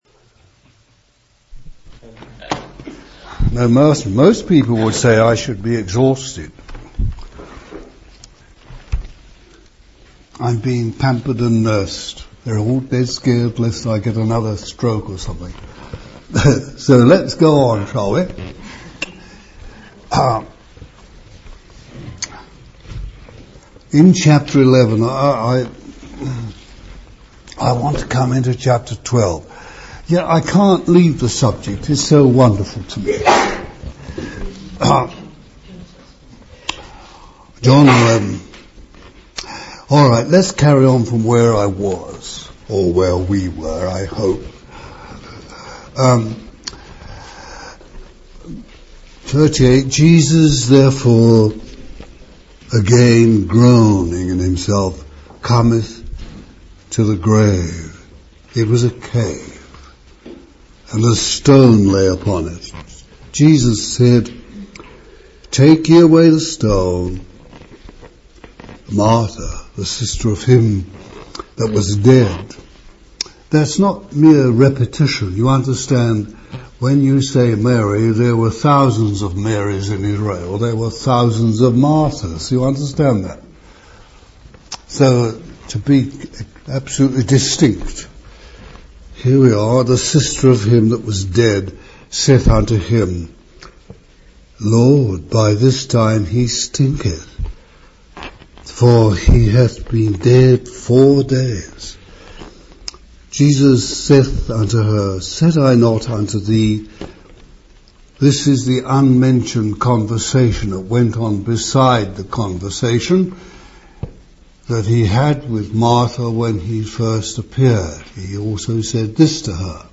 The sermon transcript discusses the importance of not building anything of wood, hay, and stubble, but instead allowing the Lord to work and have His way.